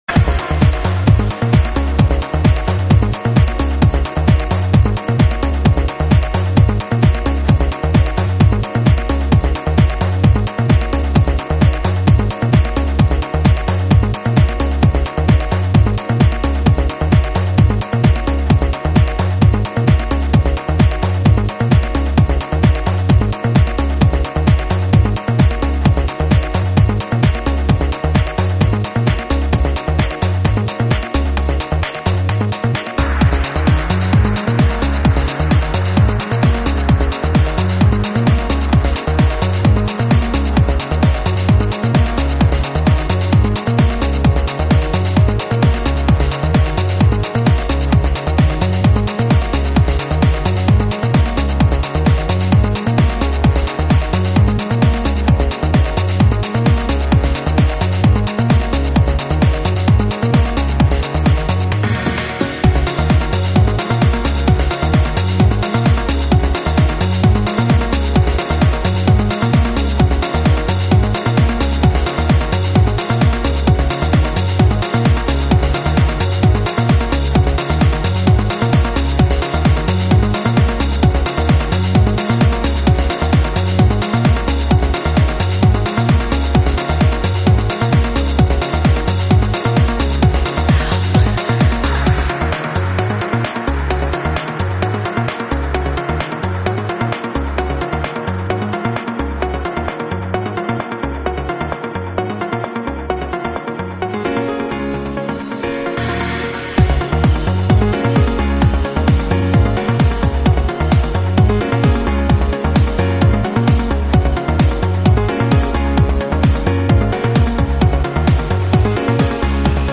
транс сборник